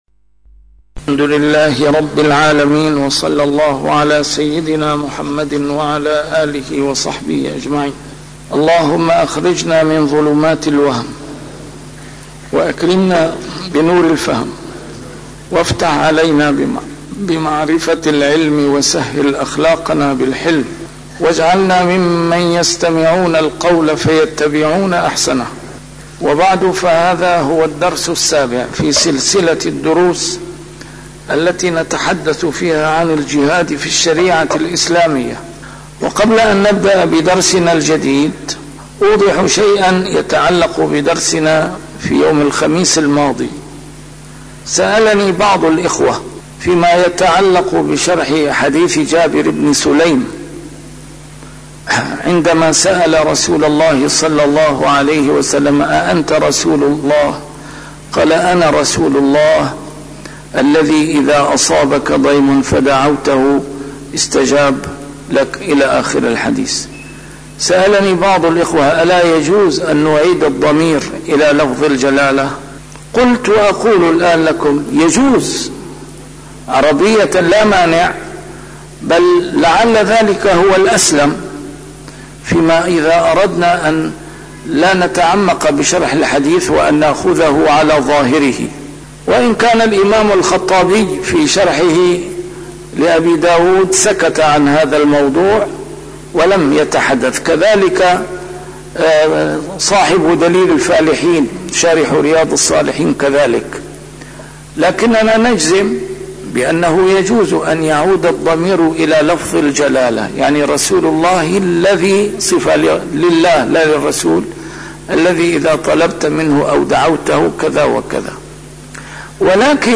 A MARTYR SCHOLAR: IMAM MUHAMMAD SAEED RAMADAN AL-BOUTI - الدروس العلمية - الجهاد في الإسلام - تسجيل قديم - الدرس السابع: دار الإسلام والمجتمع الإسلامي